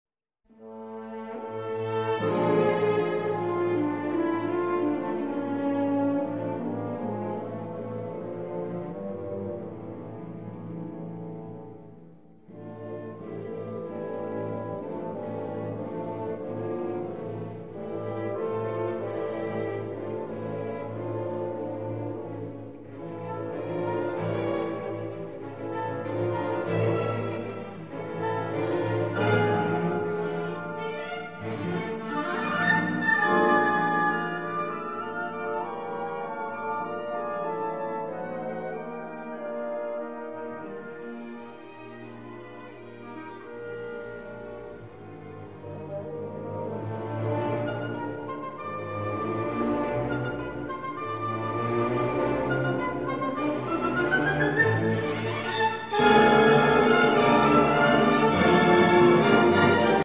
Orchestral Works